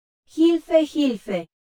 ggl_es-US-Neural2-A_*075.wav